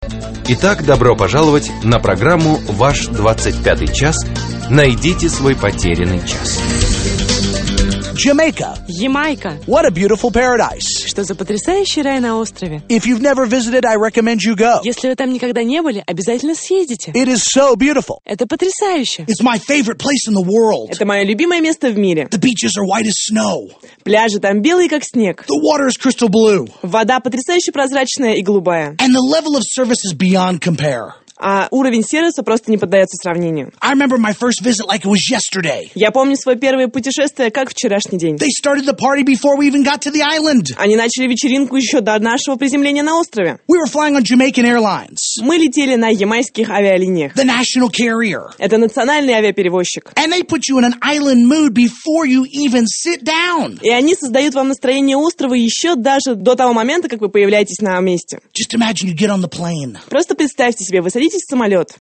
Аудиокнига Ваш 25-й час | Библиотека аудиокниг